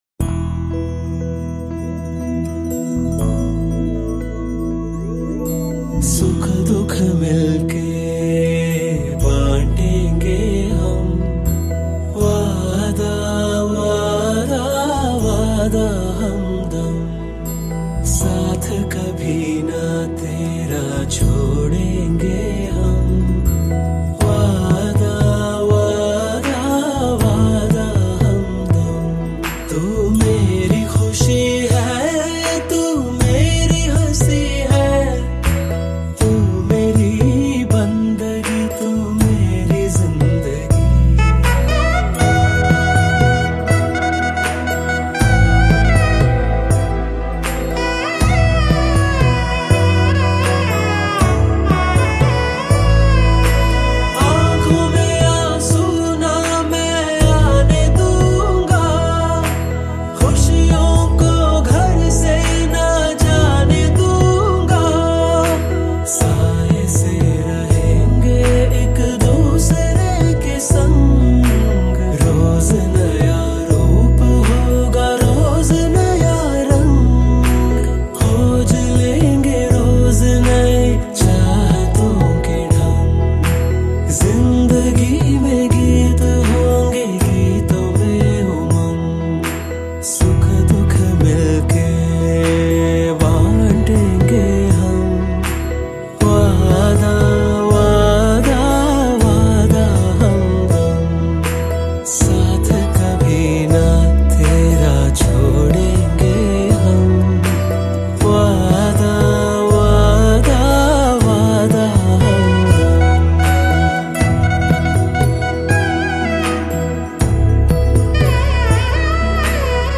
Bollywood Mp3 Music 2014